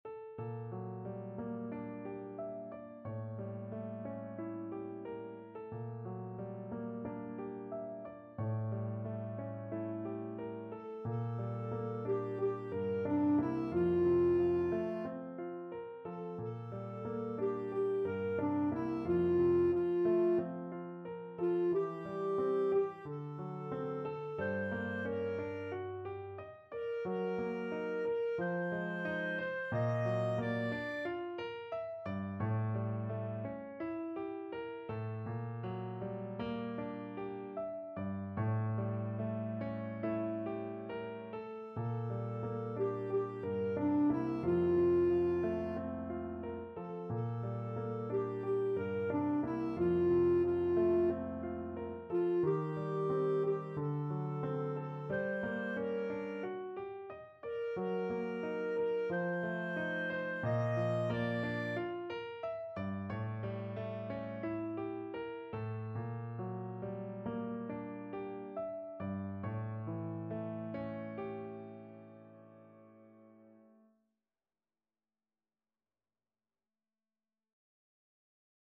Classical
Time Signature: 2/4
Tempo Marking: ~ = 45 Langsam, zart
Score Key: F major (Sounding Pitch)
Range: D5-Eb6